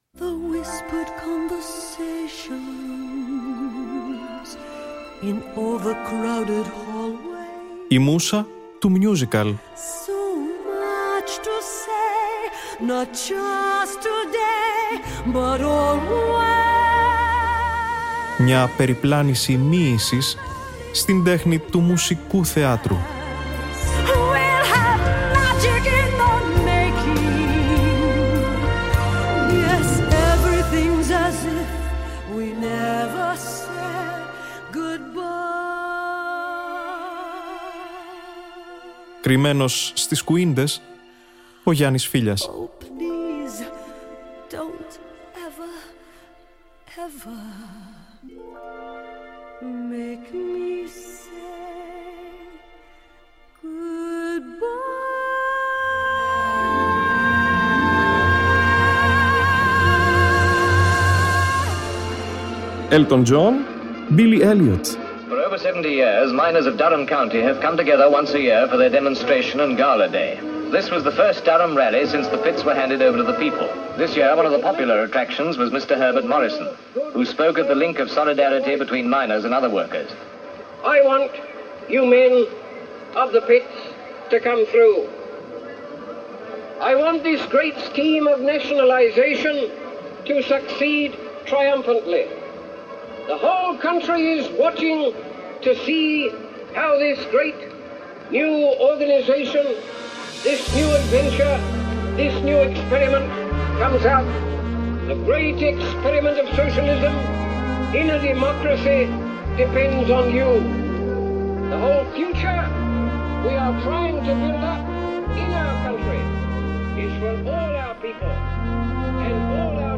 Απολαύστε τους στην εκπομπή της Τετάρτης 10 Απριλίου στη «Μούσα του Musical», μαζί με το υπόλοιπο original London cast της πρεμιέρας.